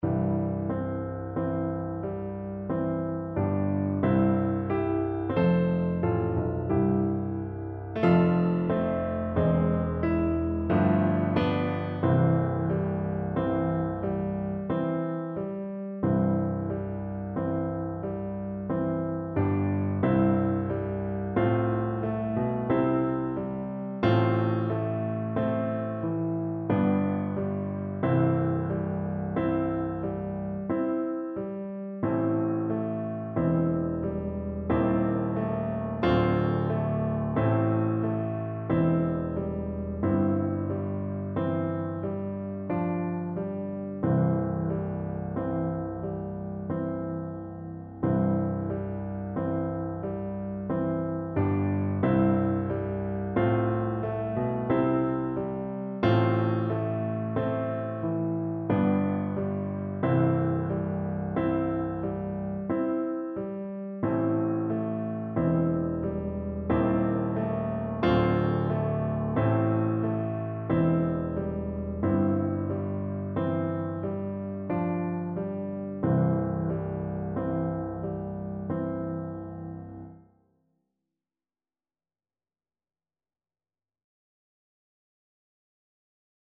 World Asia Japan Aka Tombo
Play (or use space bar on your keyboard) Pause Music Playalong - Piano Accompaniment Playalong Band Accompaniment not yet available transpose reset tempo print settings full screen
Flute
C major (Sounding Pitch) (View more C major Music for Flute )
Lento e tranquillo (=60)
3/4 (View more 3/4 Music)
Japanese